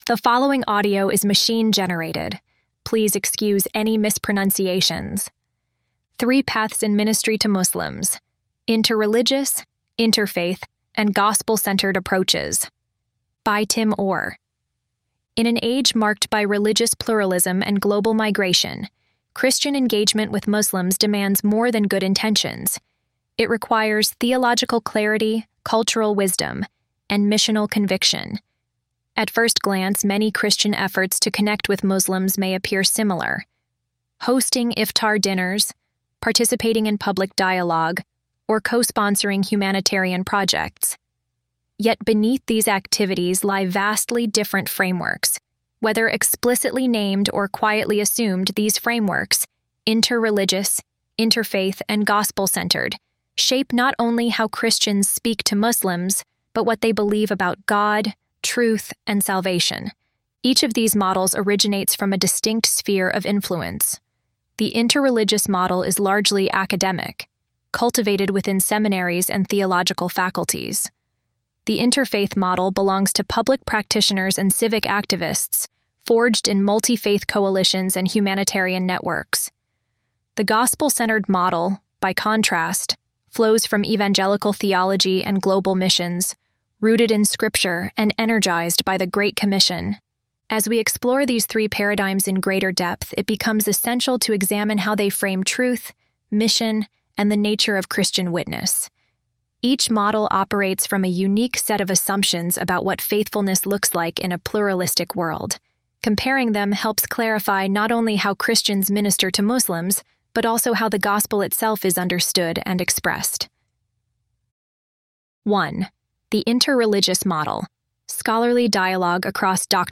ElevenLabs_Untitled_project-25.mp3